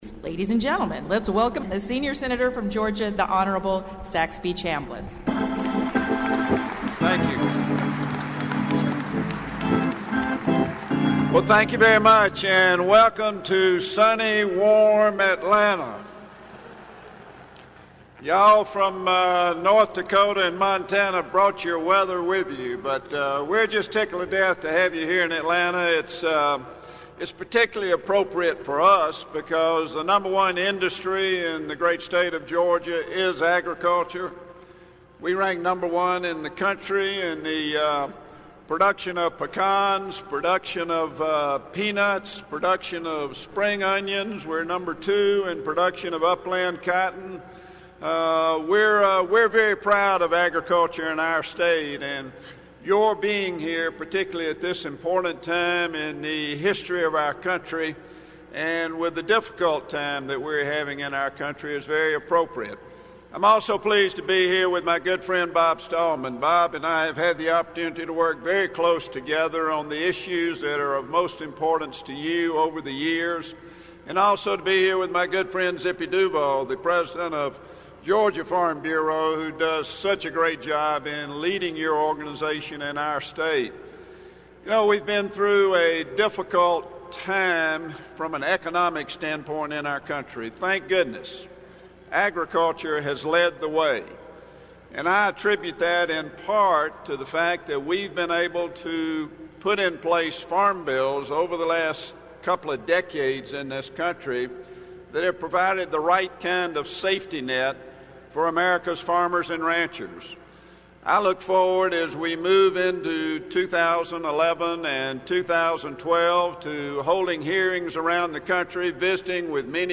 Sen. Saxby Chambliss (R-GA) welcomed the huge American Farm Bureau crowd of farmers and ranchers from around the country to his “sunny, warm” home state.
Chambliss, who is ranking Republican on the Senate Ag Committee, told the AFBF annual meeting that he will be holding hearings around the country in the coming year as Congress begins to write the 2012 Farm Bill.